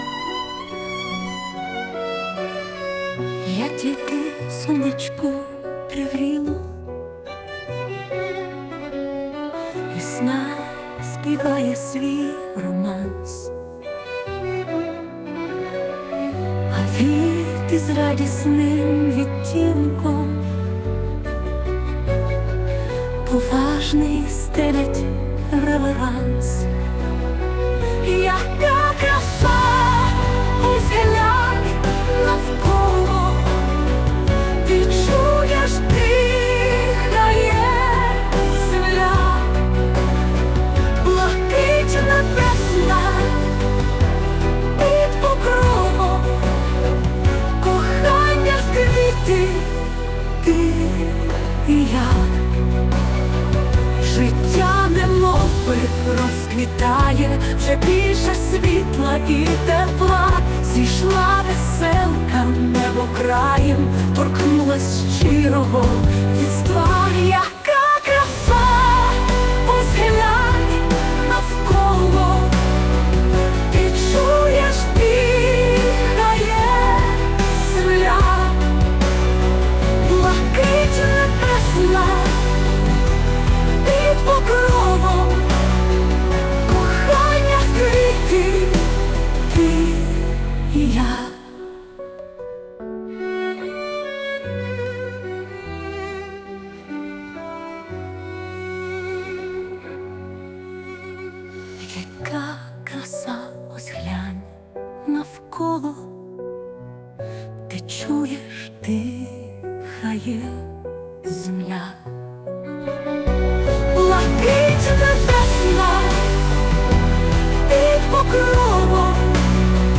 SUNO AI